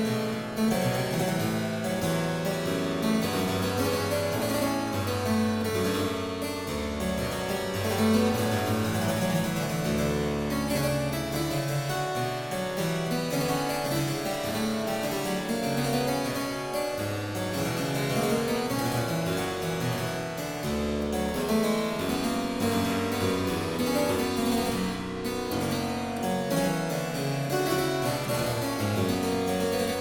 clavecin